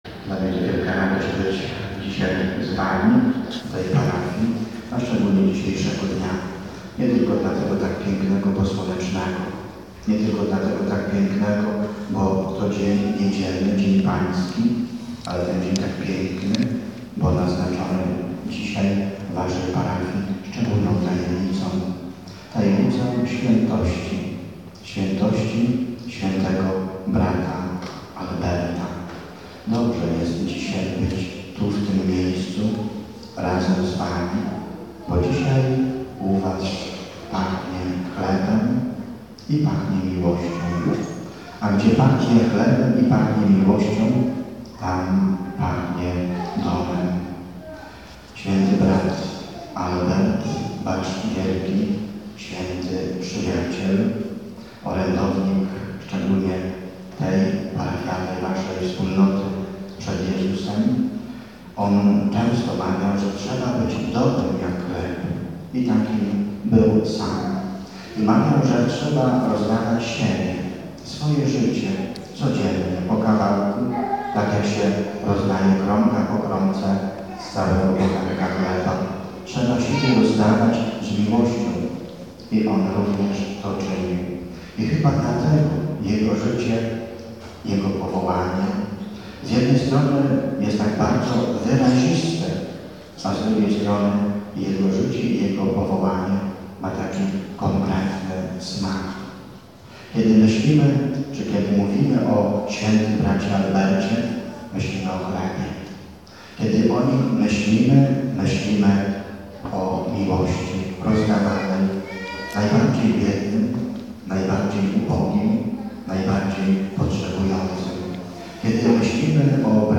Msza św. (suma) – homilia